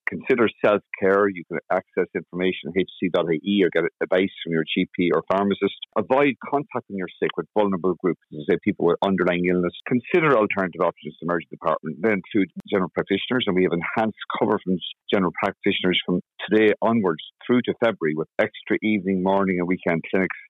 Chief Clinical Officer Dr. Colm Henry has this advice.